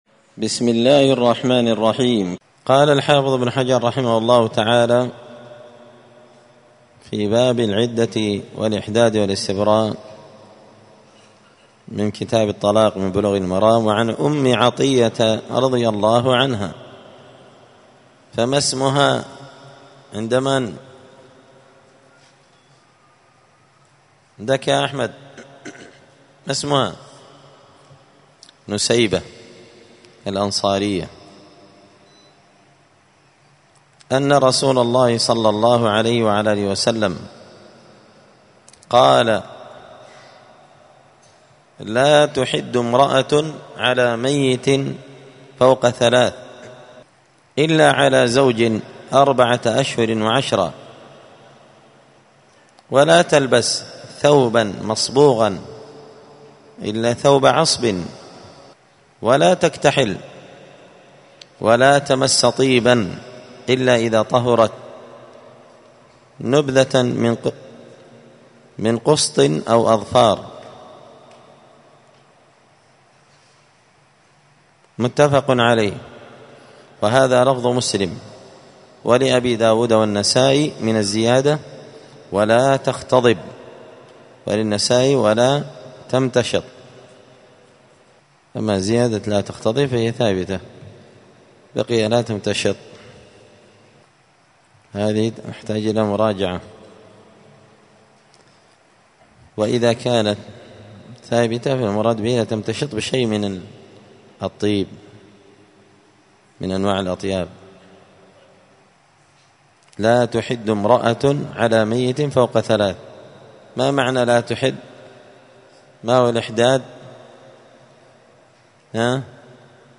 *الدرس العشرون (20) {تابع لباب العدة الإحداد والاستبراء}*